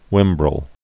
(wĭmbrəl, hwĭm-)